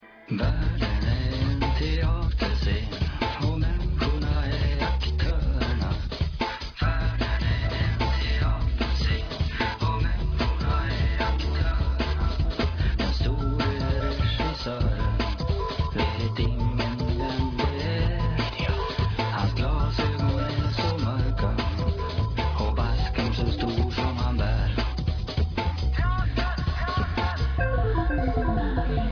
sång
syntdrops
kontrabas
2000 och 2001 i Atlantis studio 2